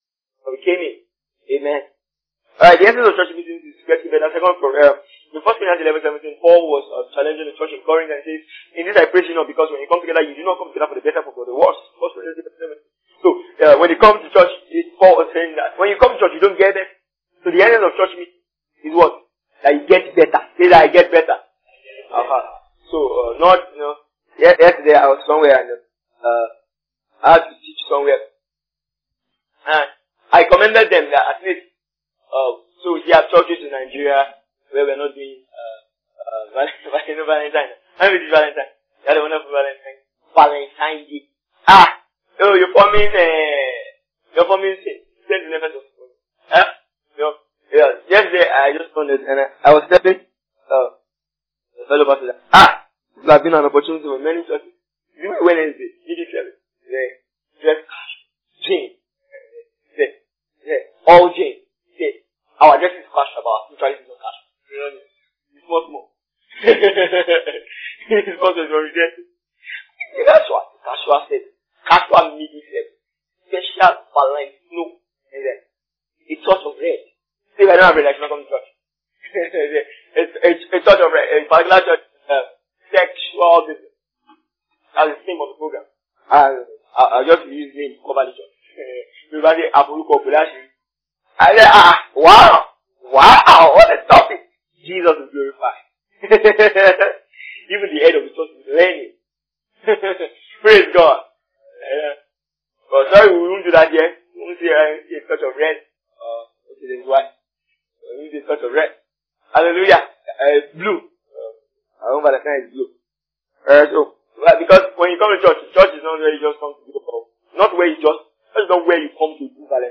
El-Shaddai is a teaching that encourages the believer to believe the Father (the all-sufficient God) to meet his needs supernaturally